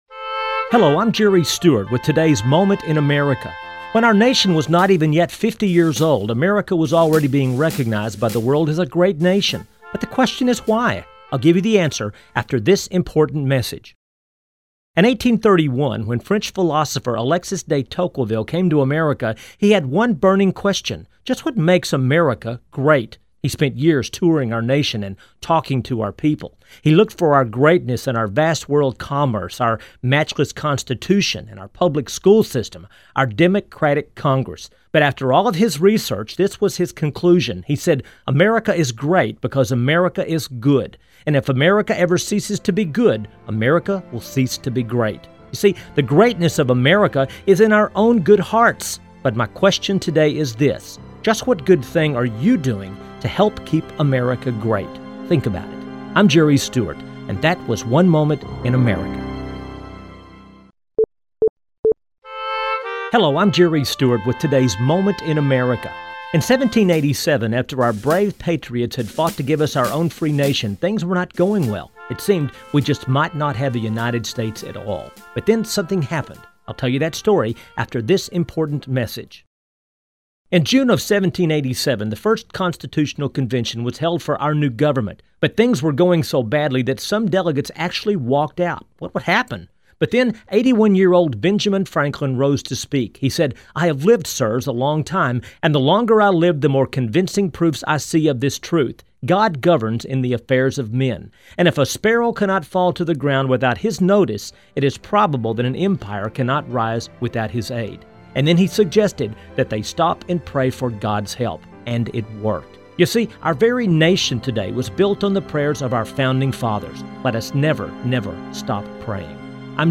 Show Demo